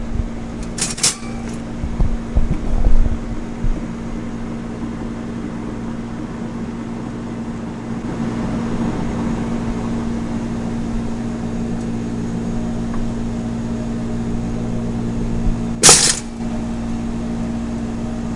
烤面包机编辑
描述：记录烤面包机